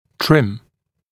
[trɪm][трим]обрезать кромки; снимать заусенцы; подгонять по форме и размеру, удаляя лишнее (напр. при припасовке съемного аппарата)